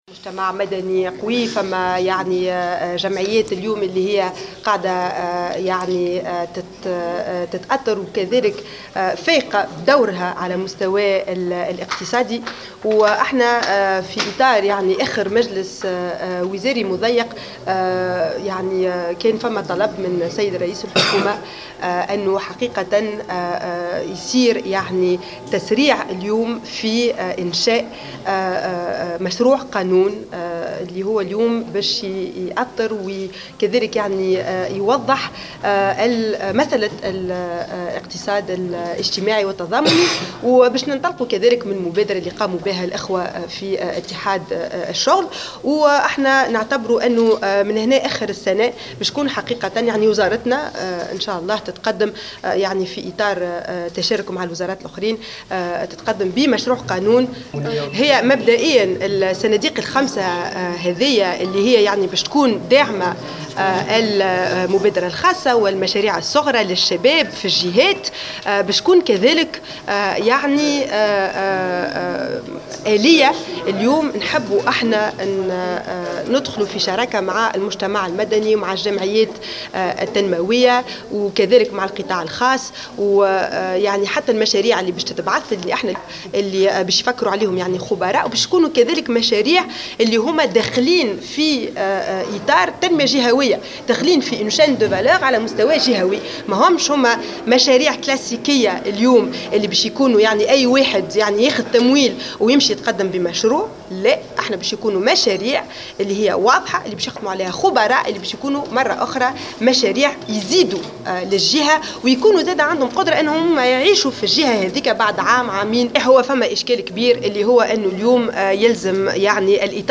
أكدت كاتبة الدولة للتشغيل سيدة الونيسي في تصريح لمراسل الجوهرة اف ام اليوم...